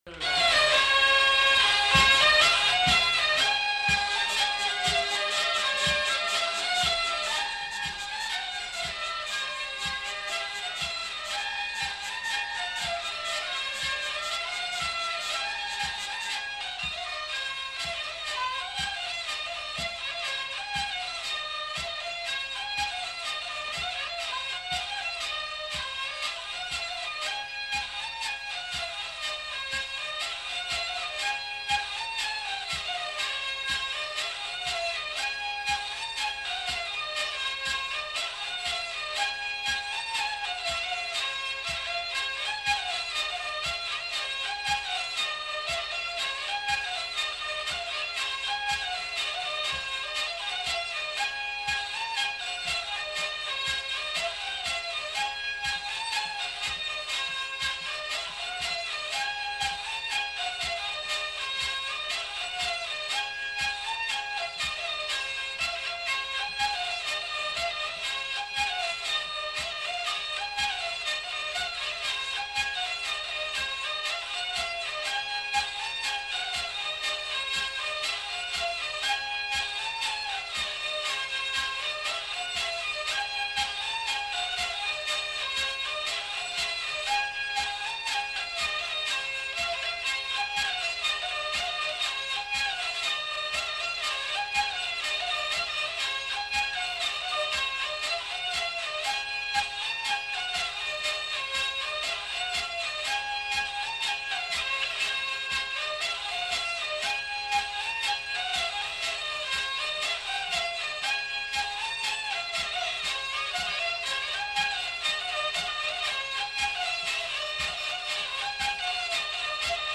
Lieu : Vielle-Soubiran
Genre : morceau instrumental
Instrument de musique : vielle à roue
Danse : rondeau